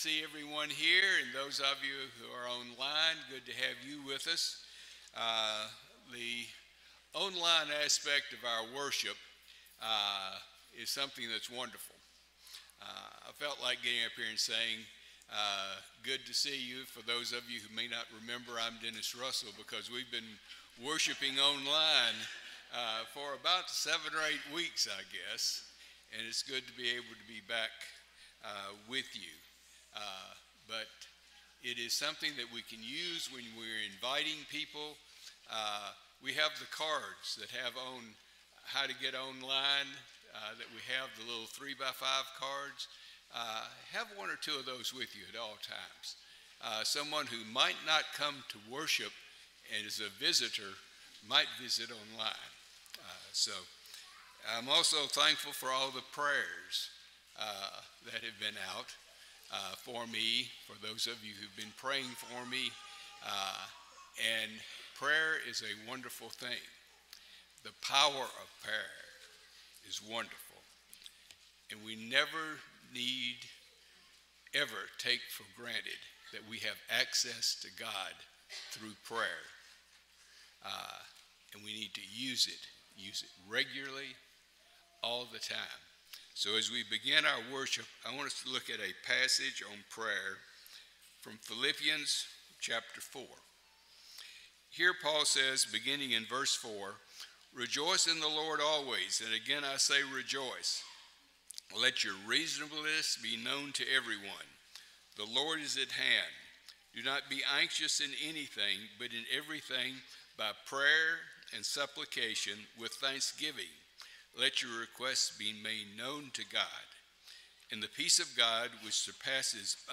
John 10:10, English Standard Version Series: Sunday AM Service